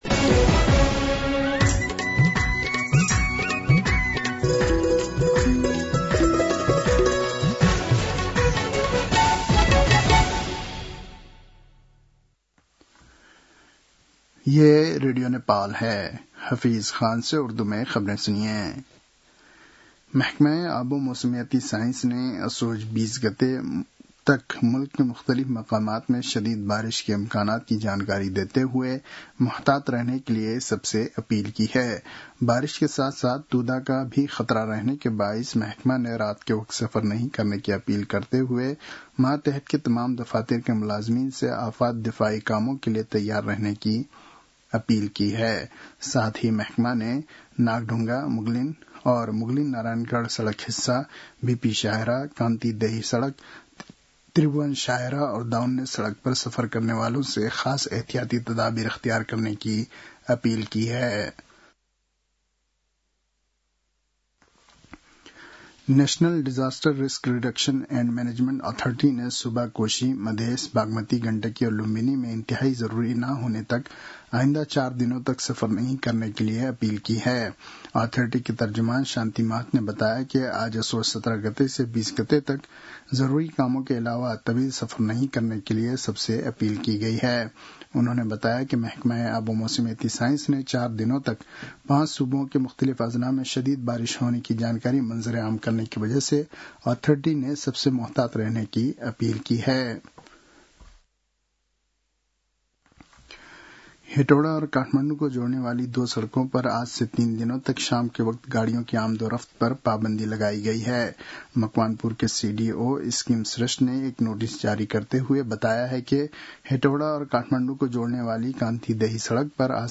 उर्दु भाषामा समाचार : १७ असोज , २०८२